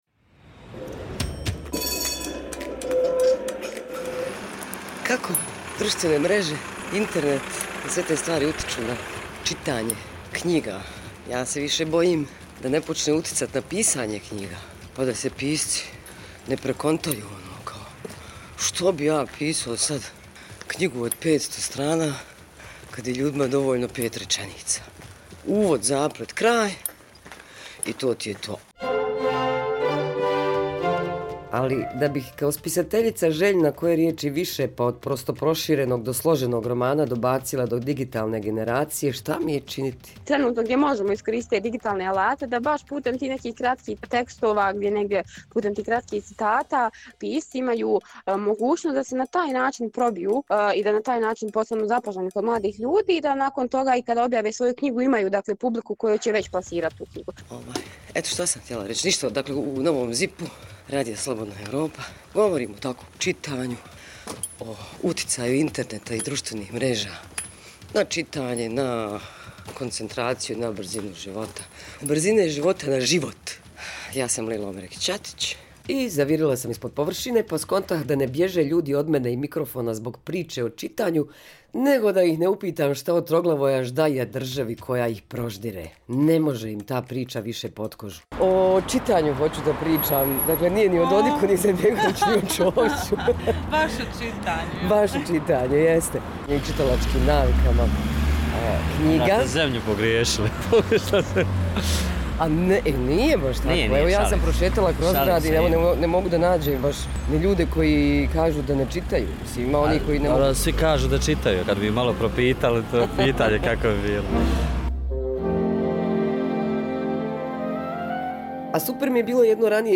od profesora, književnika, čitalaca, slučajnih prolaznika na ulici